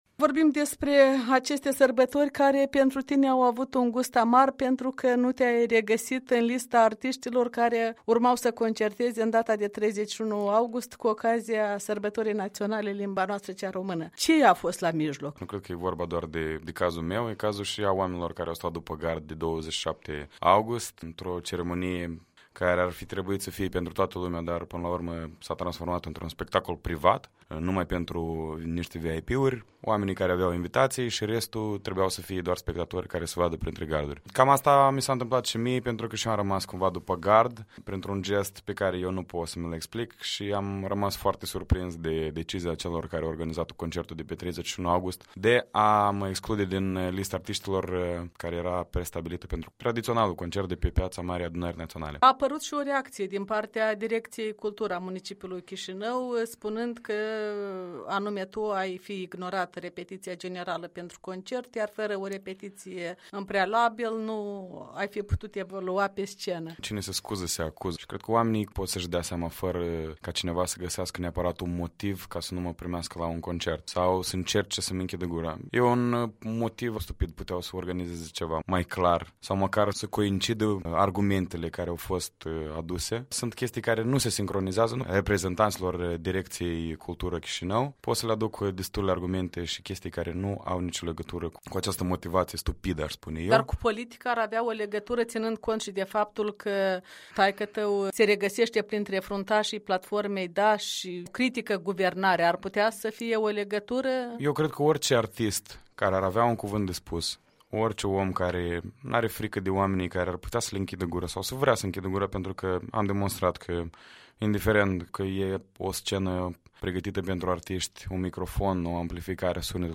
Un interviu cu artistul eliminat dintre cei care au evoluat la concertul din Piața Marii Adunări Naționale de Ziua Limbii Noastre cea Română.